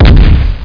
CANNON02.mp3